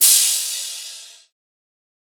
Cym (Sizzle).wav